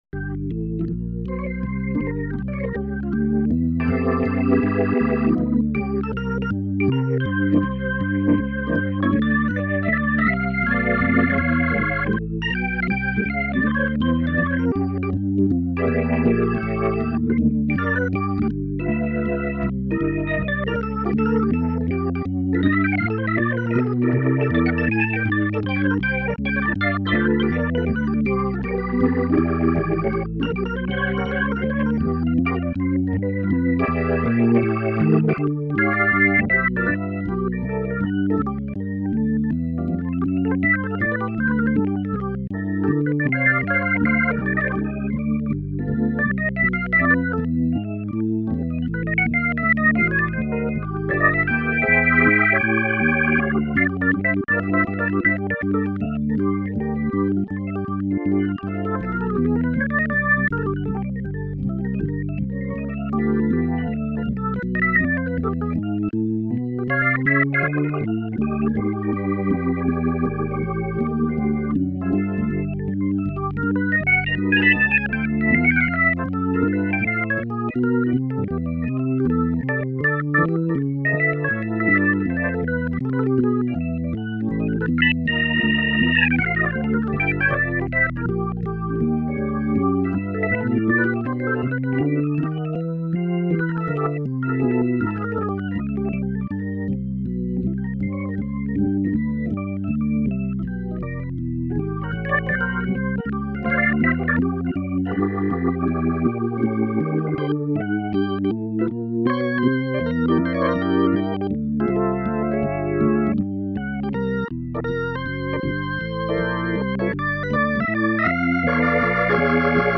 I'm going to try to snag a questionably legal copy of B4 - supposedly a really good Hammond B3 organ synth.
this demo of the product, and I'm quite impressed at the Leslie speaker simulation. Donald Leslie speakers were a huge part of the signature Hammond sound, and from the demo, it seems to have been captured pretty well.
yeah, b4 sounds great.